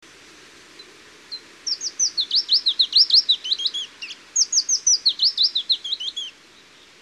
Sekalaulava uunilintu / A song switching Phylloscopus warbler
Äänite 4 Pajulintumaista laulua Recording 4 Willow Warbler-like song